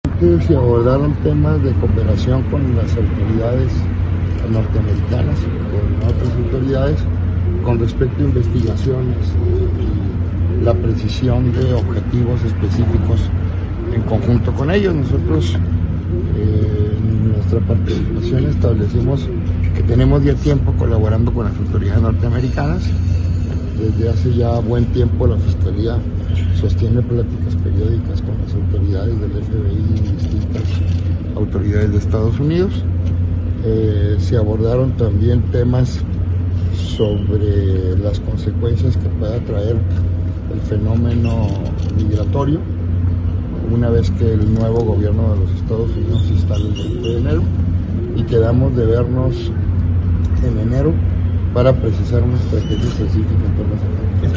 AUDIO: CÉSAR JAÚREGUI MORENO, FISCAL GENERAL DEL ESTADO (FGE)